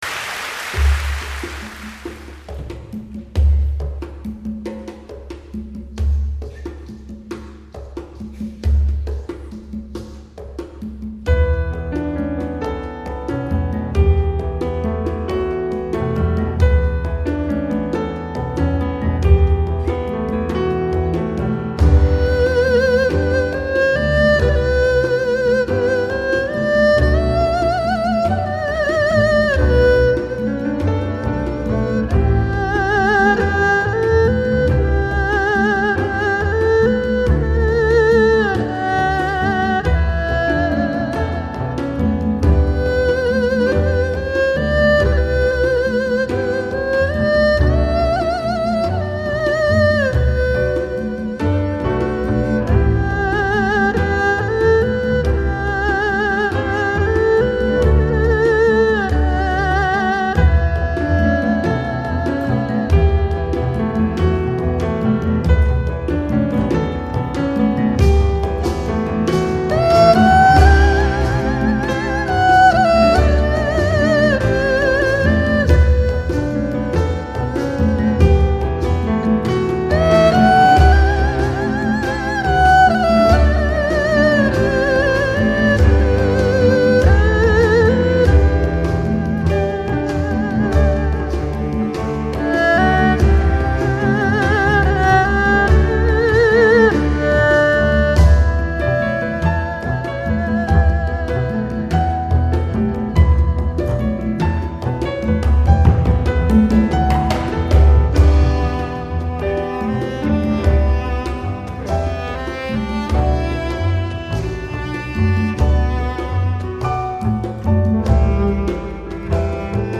现场音乐会的部分录音合辑